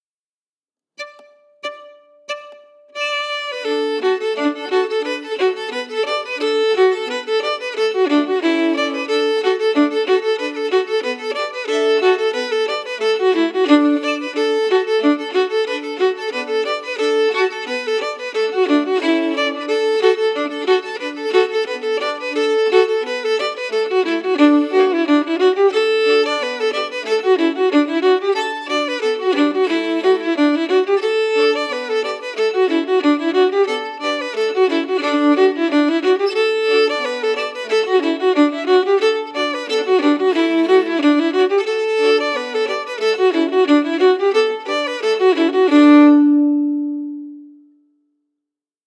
Key: D*
Form: Reel
Melody emphasis
Region: Acadia, Maritime Canada, PEI? New Brunswick?